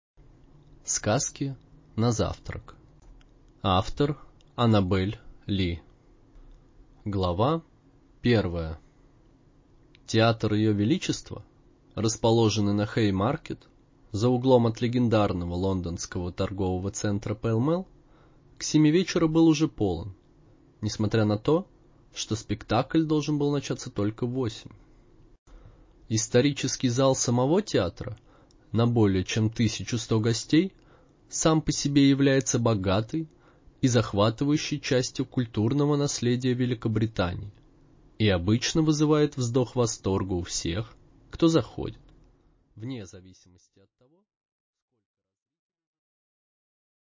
Аудиокнига Сказки на завтрак | Библиотека аудиокниг